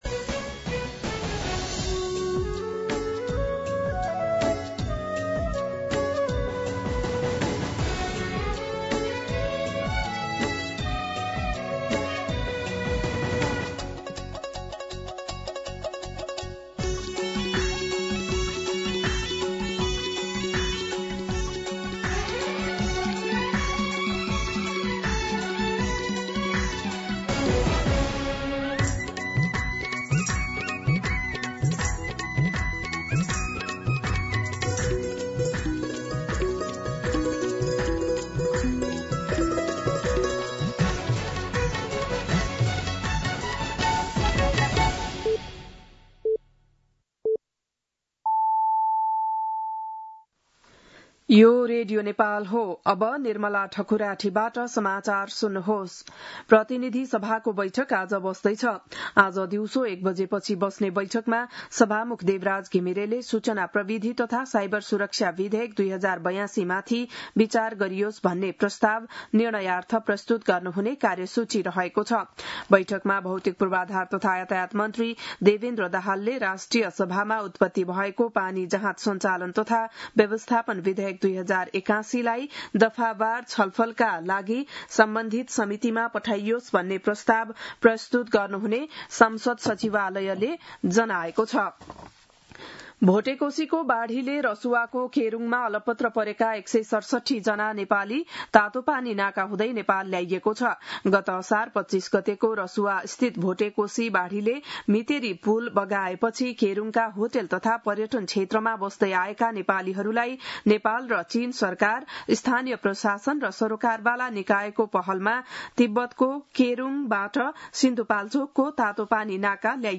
बिहान ११ बजेको नेपाली समाचार : २९ साउन , २०८२
11-am-Nepali-News-1-1.mp3